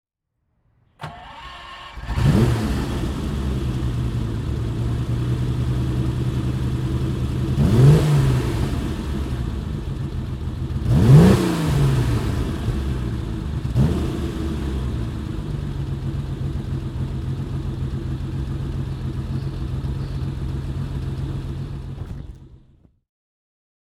• With the classic twelve-cylinder engine
Ferrari 250 GT 2+2 (1962) - Starten und Leerlauf